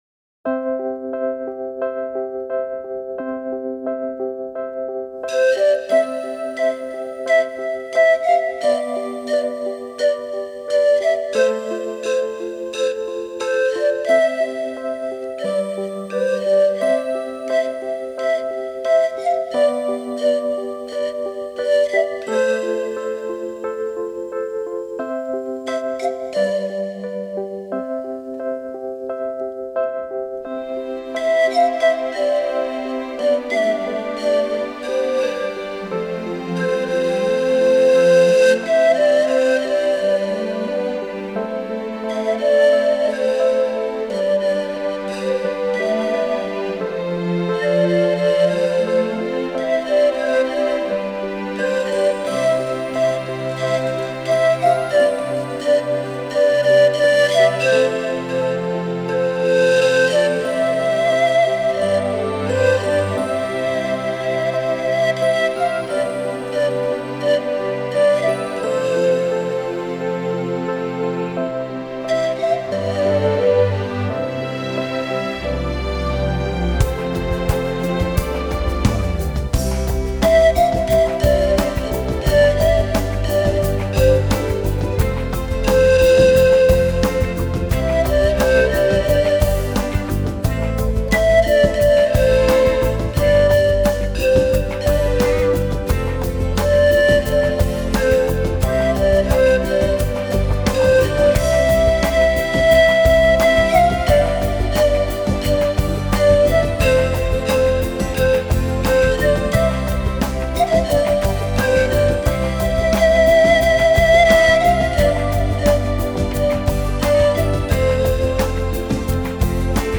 排簫） 類別：輕音樂
這些樂 曲的伴奏以吉他、鍵盤和敲擊樂器為主，襯以輕音樂管絃樂團流暢的絃樂，一段 段動人的旋律，讓人從頭舒服到腳。